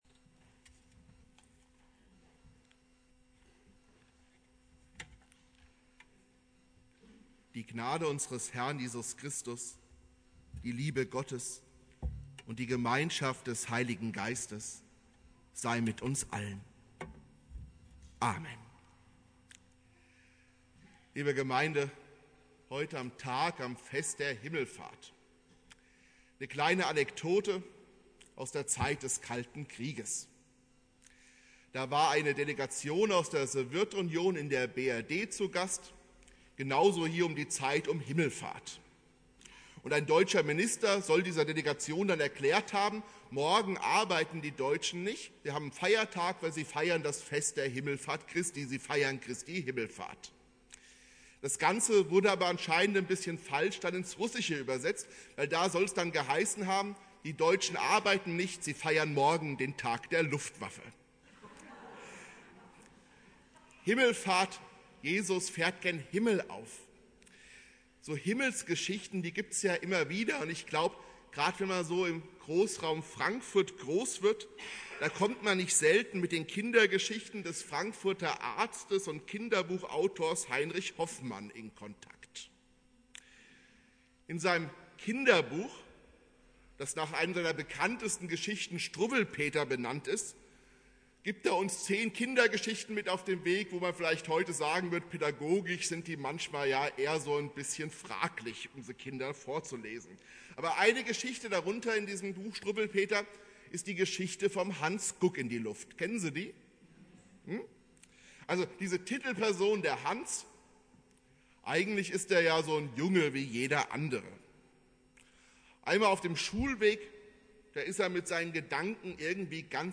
Predigt
Christi Himmelfahrt